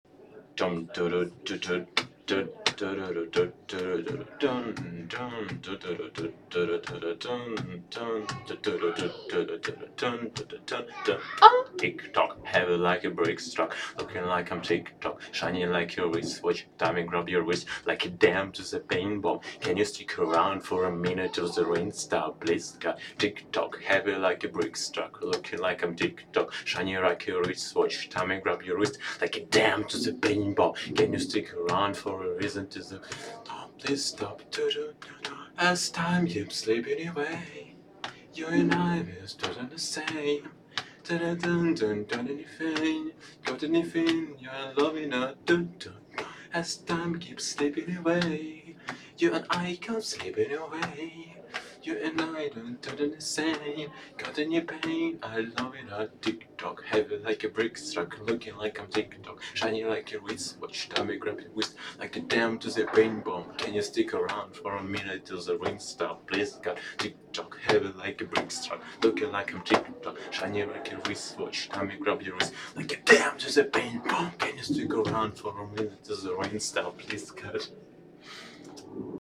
(singend)
MS Wissenschaft @ Diverse Häfen